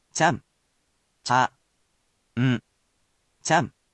He’ll be here to help sound out these honorifics for you.